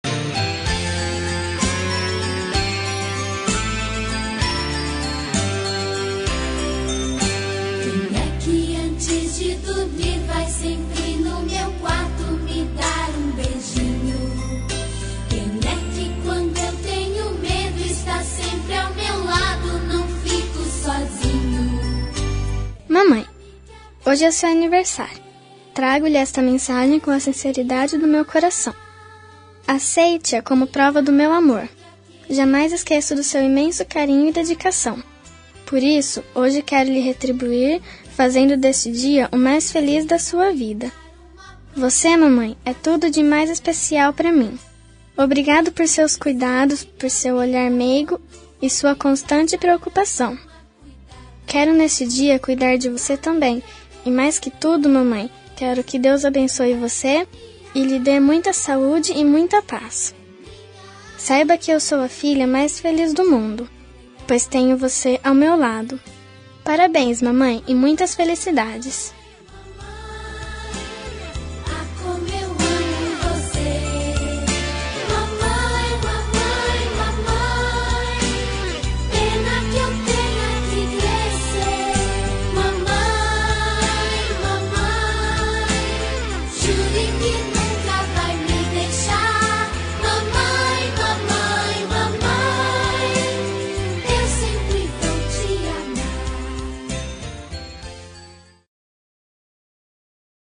2570-infantil-fem-mae.m4a